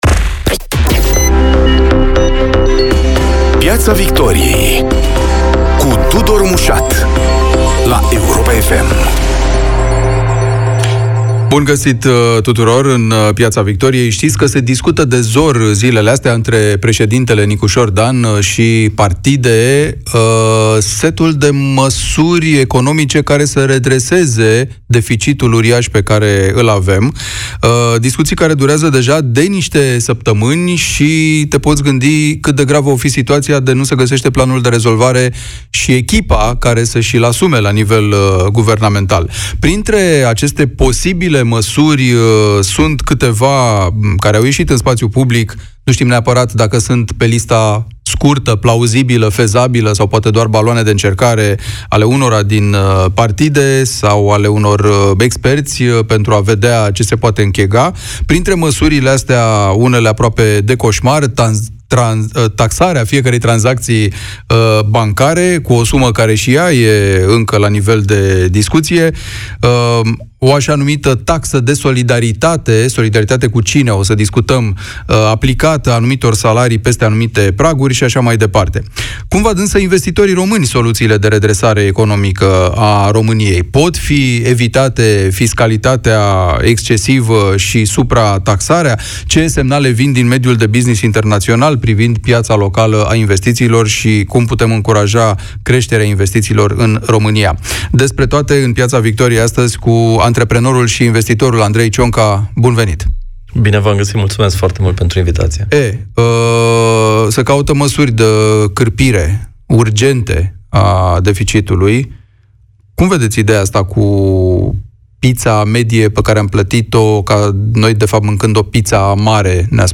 În Piața Victoriei, invitatul Ioanei Ene Dogioiu este ministrul care a convins de două ori rezistența olandeză, Cristian Diaconescu.